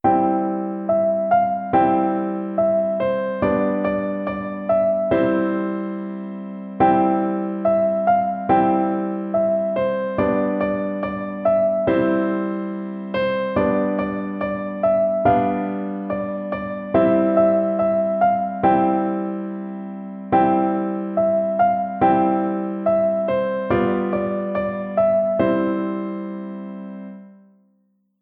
Crashkurs Klavier Online Nr. 2 → Hänsel und Gretel warten schon auf deine Hilfe - Musikschule »allégro«